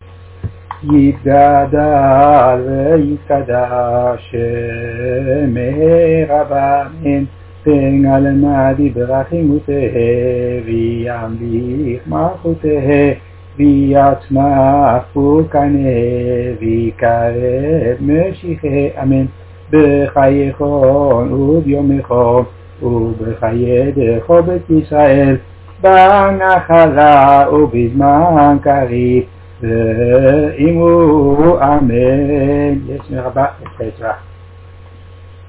melody of Rosh Hashana is used.